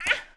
SFX_Battle_Vesna_Defense_05.wav